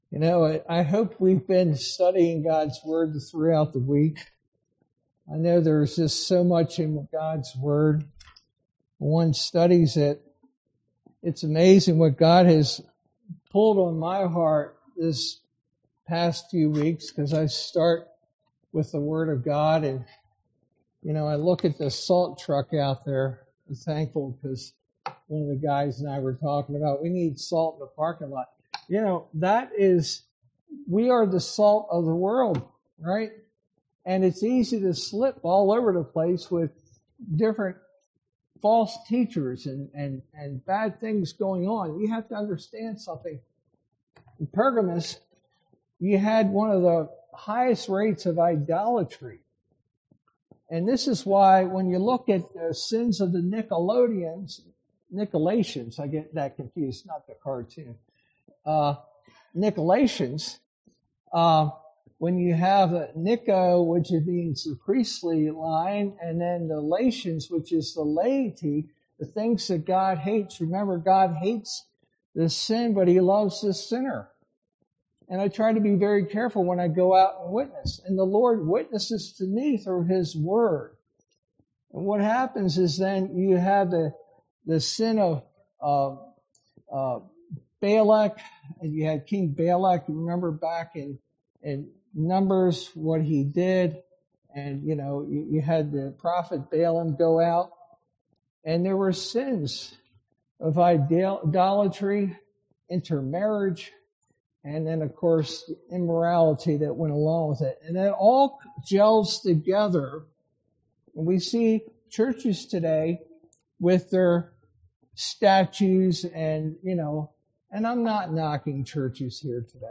Sermon verse: Revelation 2:12-17